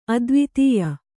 ♪ advitīya